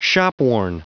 Prononciation du mot shopworn en anglais (fichier audio)
Prononciation du mot : shopworn